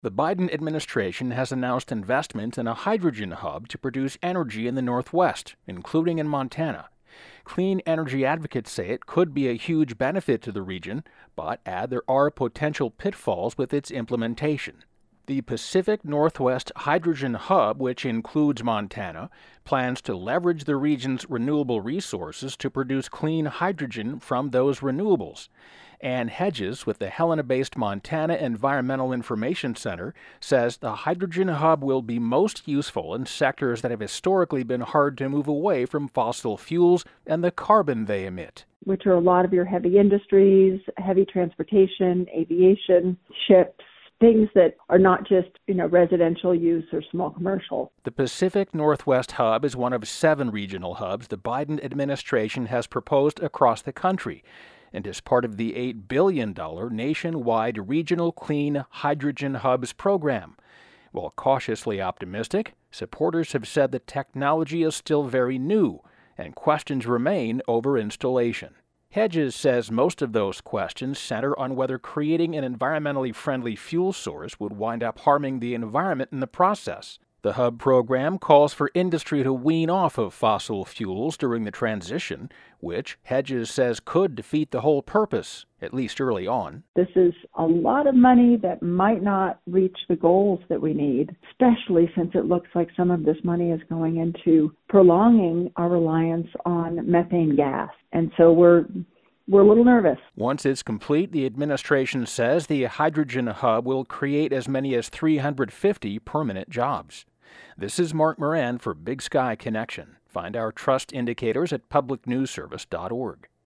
Category: City Desk